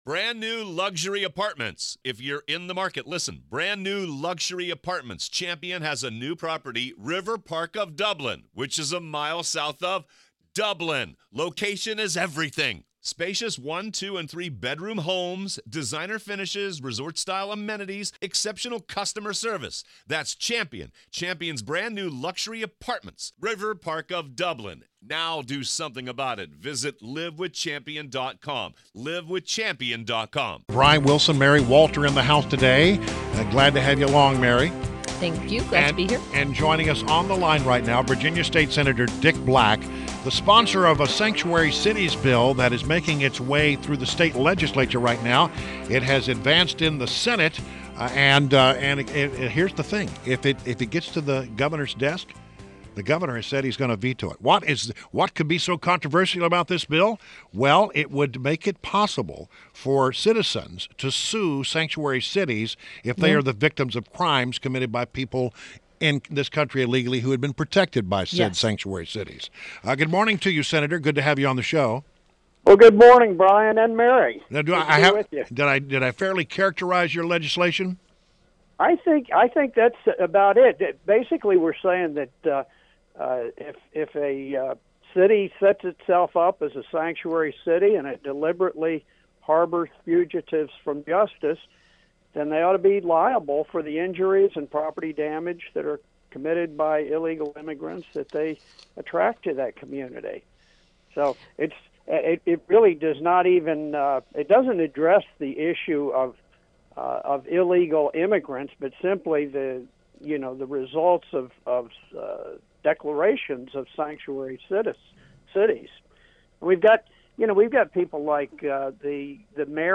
INTERVIEW – VA STATE SENATOR DICK BLACK – sponsor of one of the sanctuary cities bill